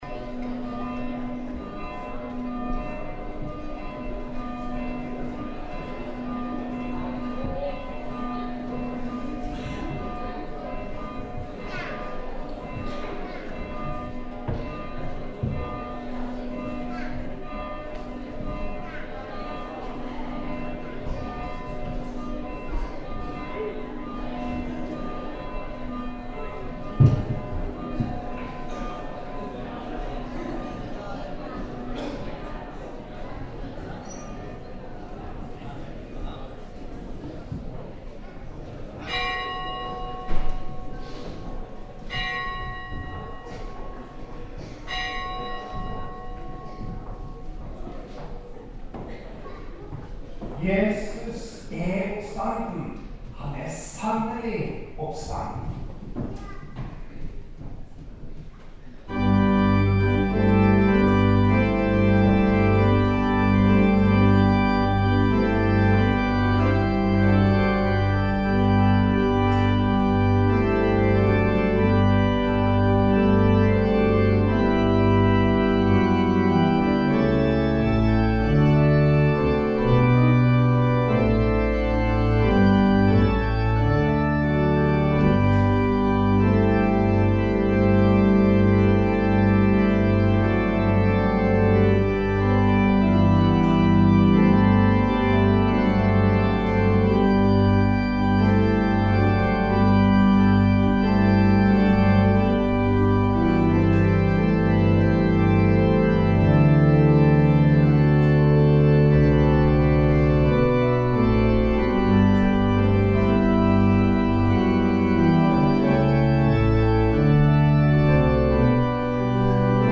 Listen to some of the hymns in church: or download as MP3